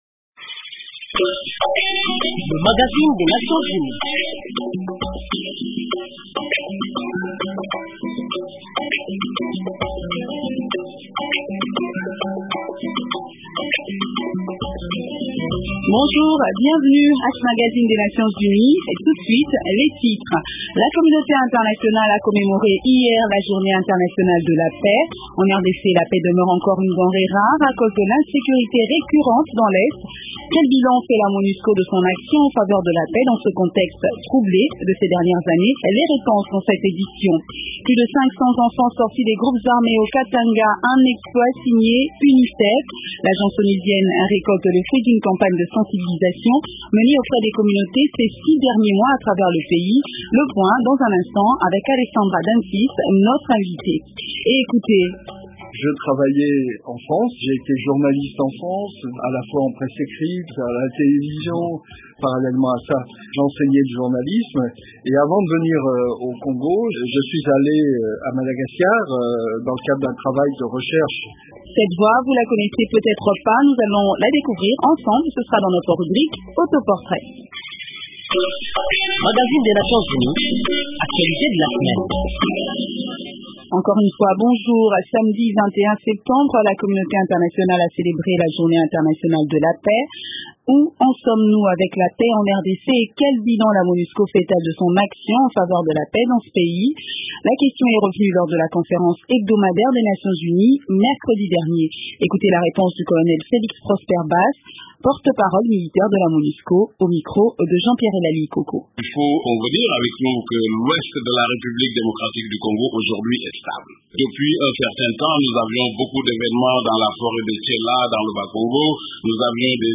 Découvrez dans ce magazine, diffusé dimanche 22 septembre, les autres sujets d’actualité de la semaine impliquant les nations unies en RDC. 2.